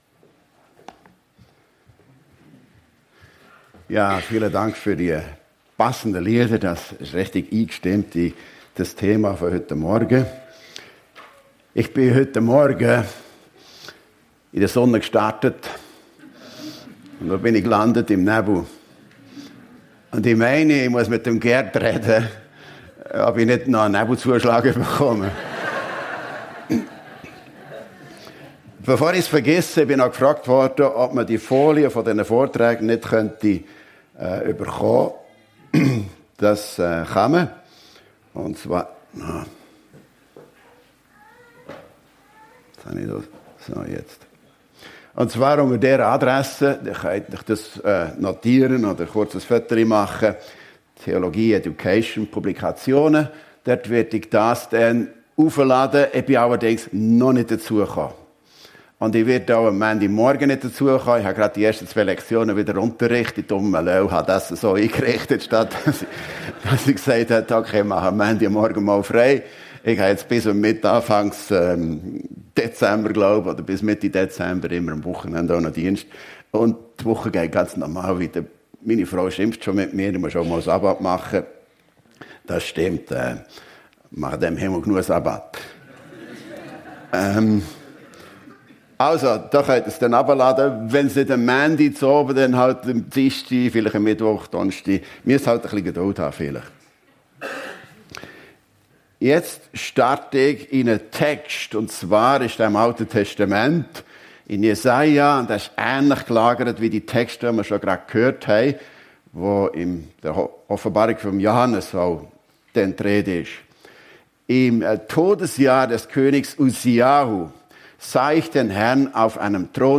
FEG Sumiswald - Predigten Podcast